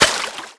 wrench_hit_liquid2.wav